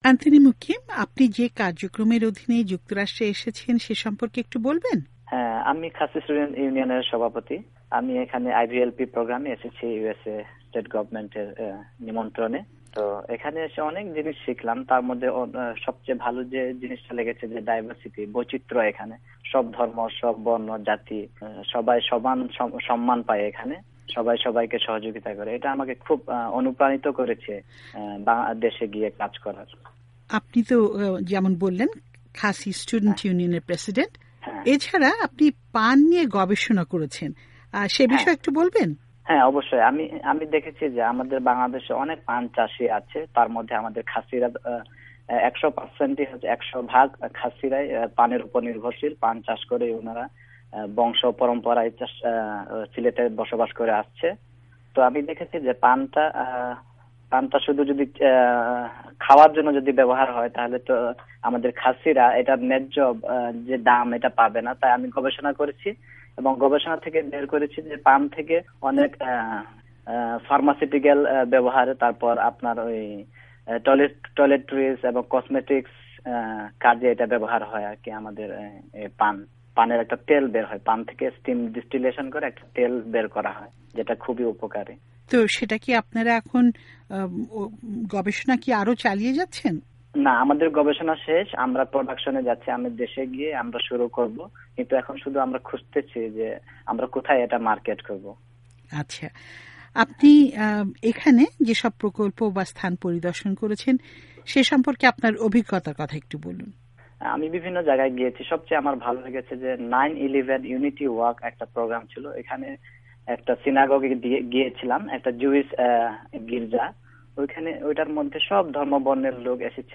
বাংলা বিভাগের সঙ্গে এক সাক্ষাৎকারে তিনি IVLP কার্যক্রম, Khasi Student Unionএর বিভিন্ন কার্যক্রম এবং যুক্তরাষ্ট্রে তার সফর সম্পর্কে বিস্তারিত বলেন।